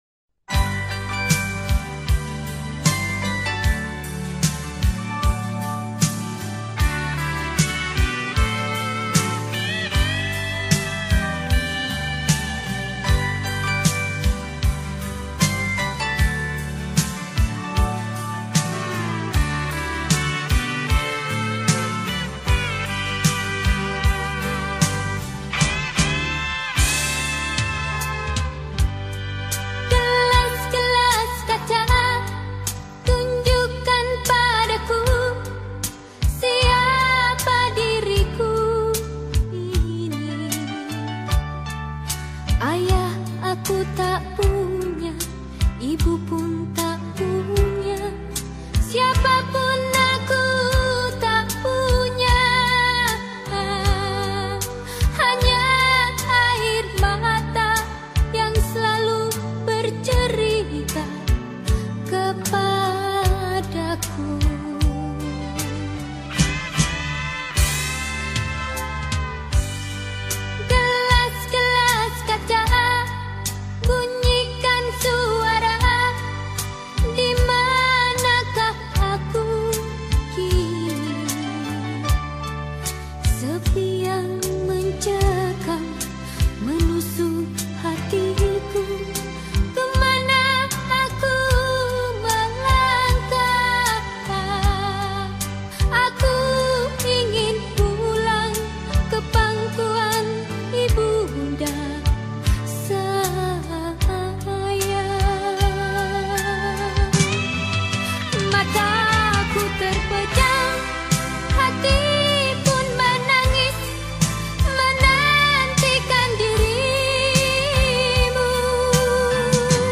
Indonesian Song